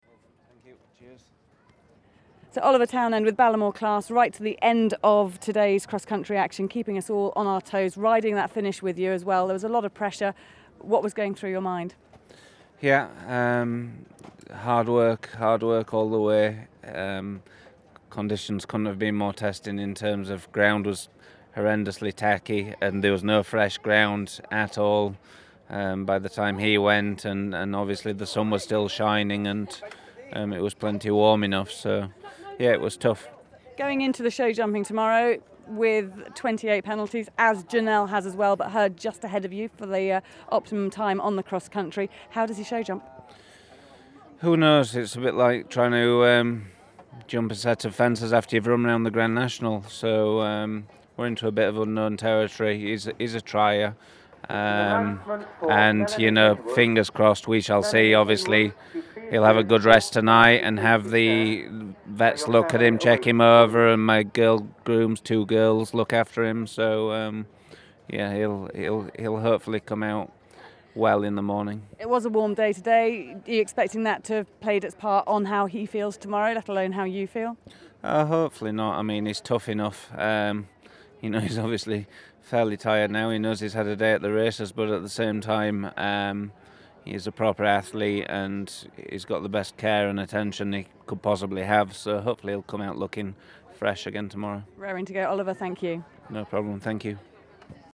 MMBHT_Oliver_Townend_End_of_Day_3_interview.mp3